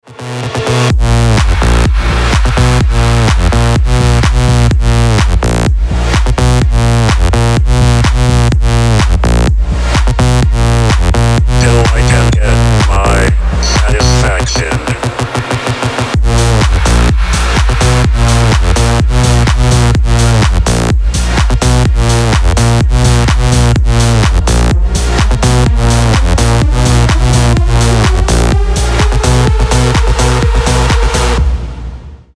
клубные , танцевальные , крутые , electro house , ремиксы